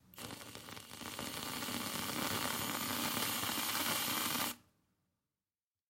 Звуки взбитых сливок
Звук плавного распыления взбитых сливок